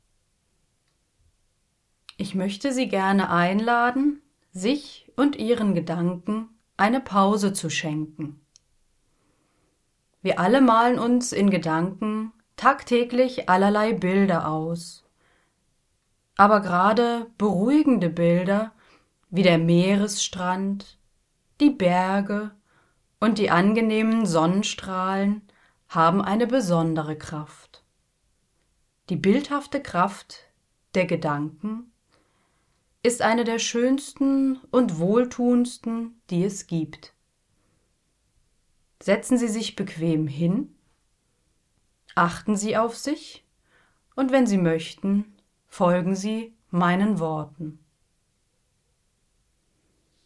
NEU: Alle Phantasiereisen jetzt in optimierter Ton-Qualtität.